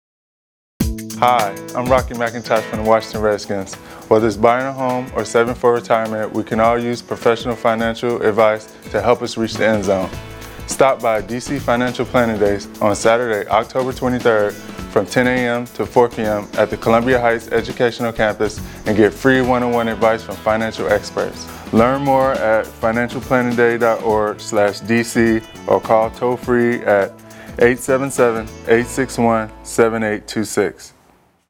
Inside Linebacker Records PSAs to Help Promote D.C. Financial Planning Day; Local Financial Planners to Offer Free, No-Strings-Attached Advice to Public